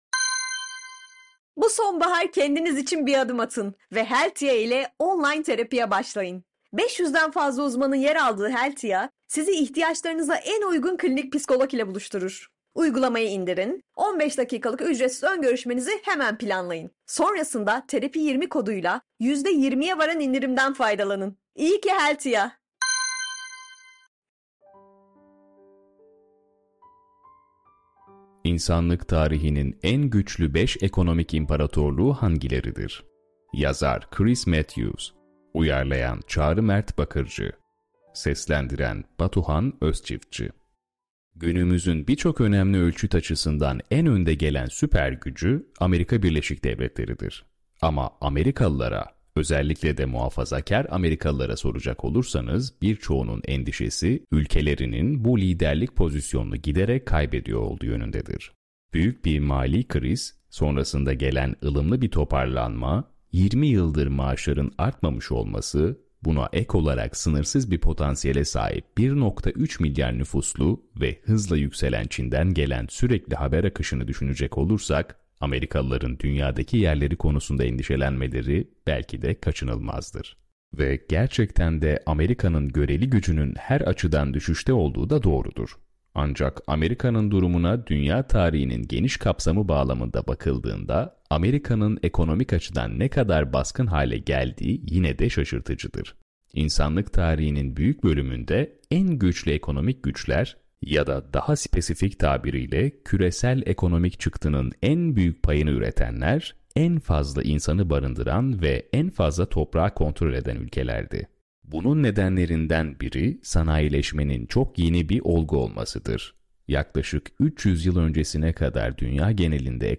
Bu yayın listemizde, Evrim Ağacı'nda yayınladığımız içeriklerden seçilmiş yazılarımızı yazarlarımızın kendileri, diğer yazarlarımız veya ses sanatçıları seslendirerek, sizlerin kulaklarına ulaştırıyor.